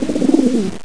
coo.mp3